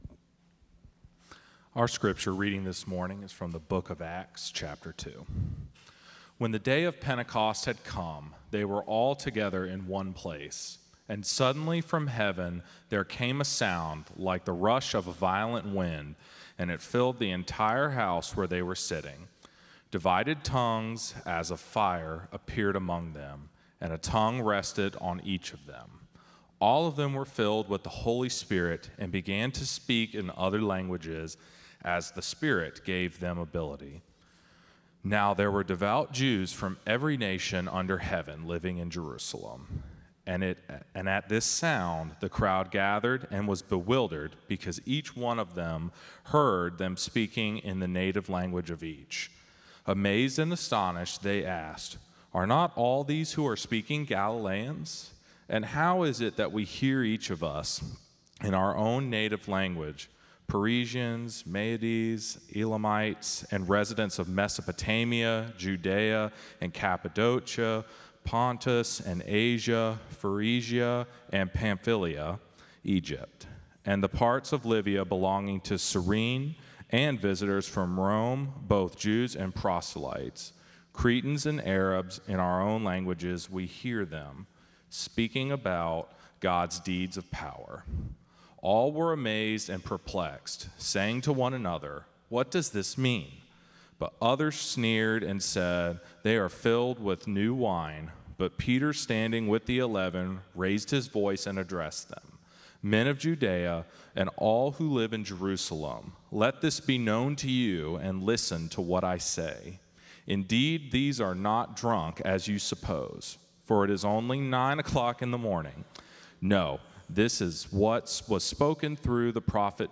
First Cary UMC's First on Chatham Sermon "Spirit on Fire&rdquo